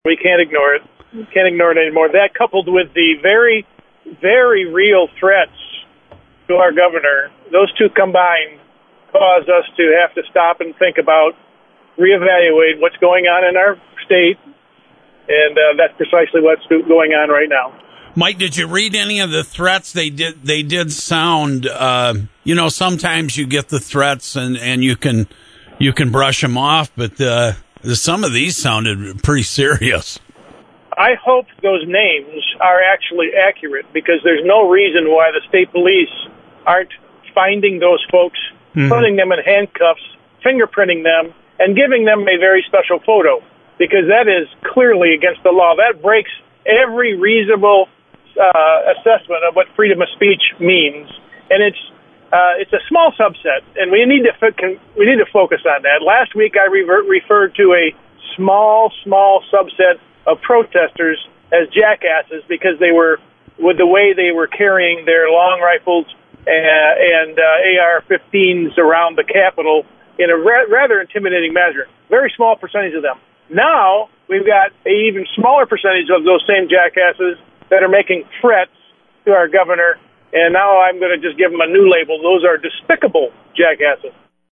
The Governor has faced death threats from social media sites and that drew outrage from Senate Majority Leader Mike Shirkey. Shirkey appeared on AM Jackson Tuesday and had harsh words for those who make threats…